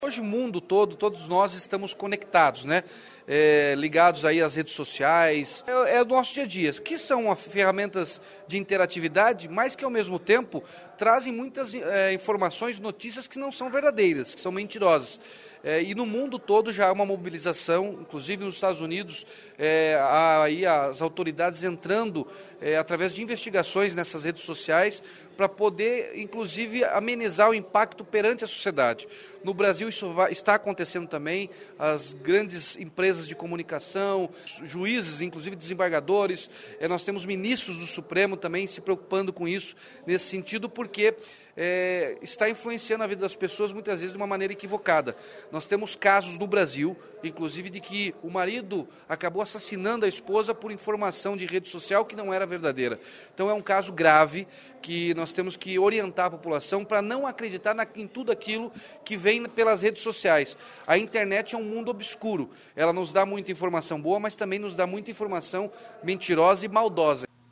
Ouça a entrevista com o parlamentar, que apresentou a publicação durante a sessão plenária desta terça-feira (24).(Sonora)